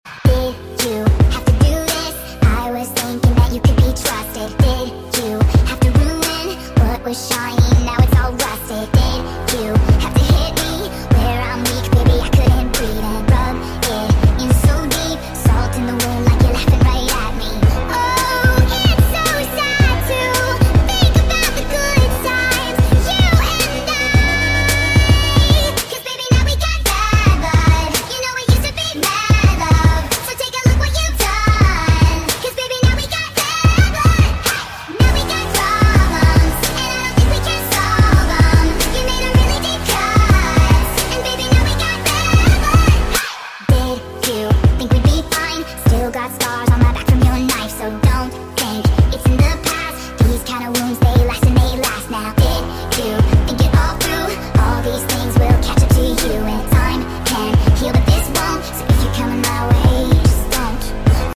(sped up)